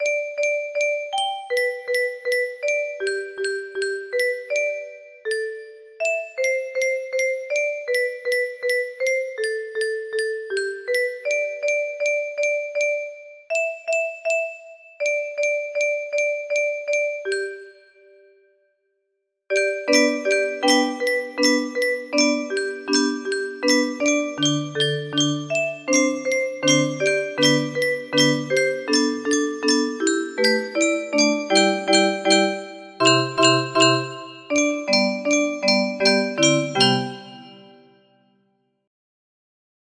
Zag twee beren broodjes smeren music box melody